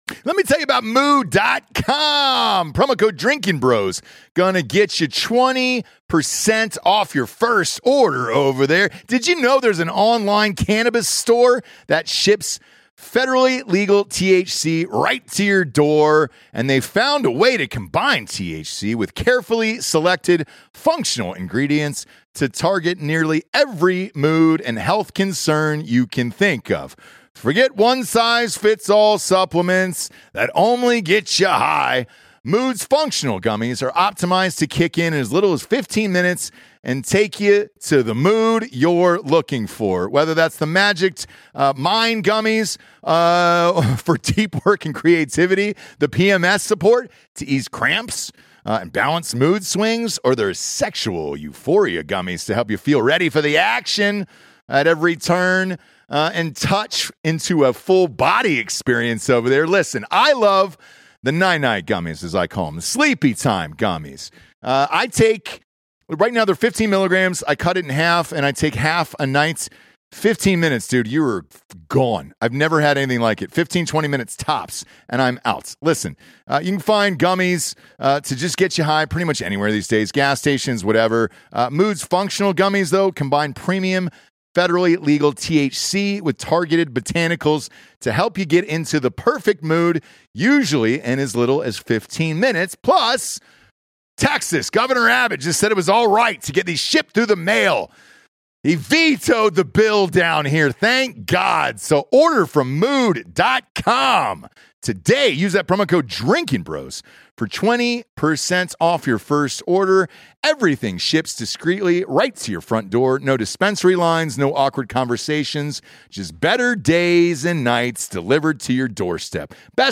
Politics, Comedy, News, Sports